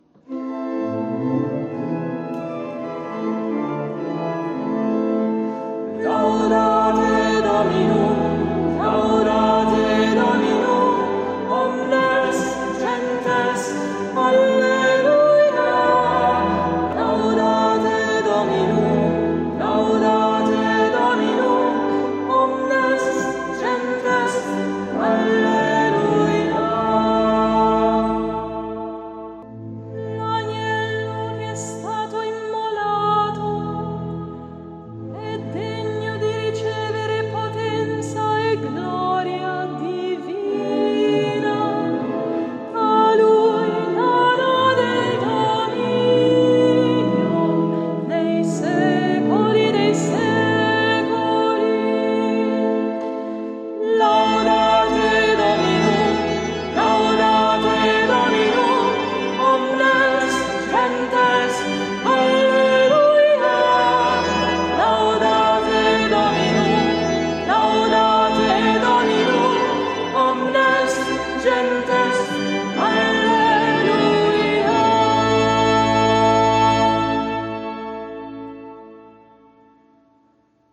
È indicato come canto generico di lode.
esecuzione a cura degli animatori musicali del Duomo di Milano